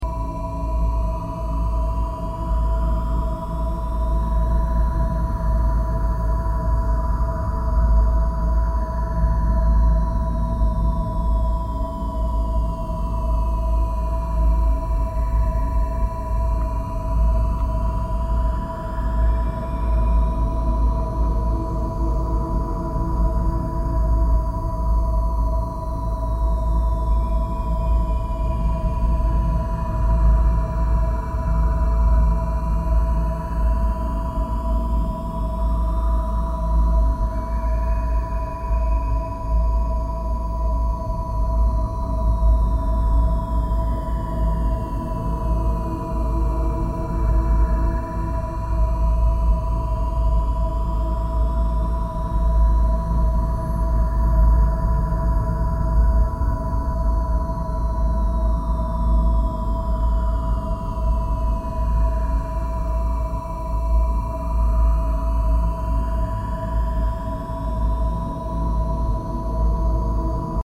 Immerse yourself in the powerful healing vibrations of 1111Hz, 963Hz, and 639Hz—three sacred frequencies harmonized to awaken cosmic consciousness, activate your divine DNA, and restore energetic balance.
Let this soundscape assist you in deep meditation, lucid dreaming, chakra healing, and inner transformation. Listen with headphones for the best experience.